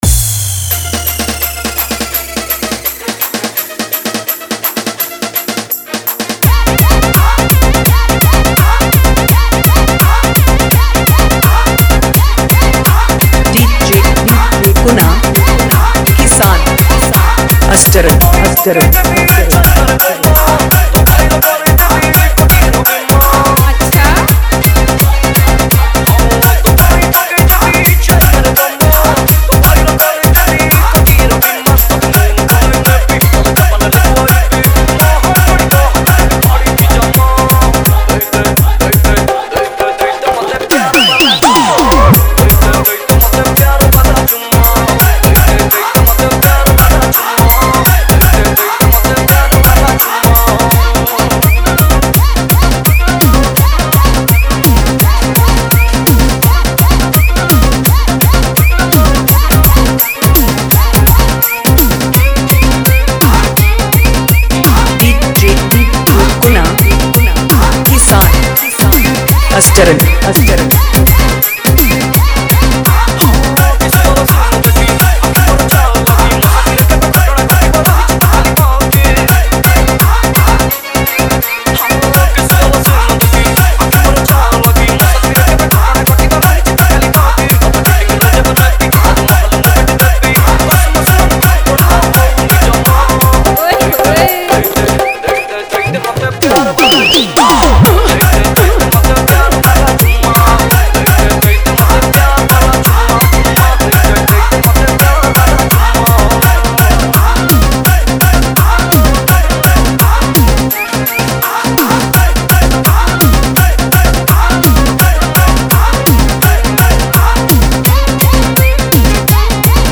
• Category:Odia New Dj Song 2017